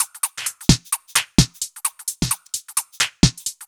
Index of /musicradar/uk-garage-samples/130bpm Lines n Loops/Beats
GA_BeatnPercE130-09.wav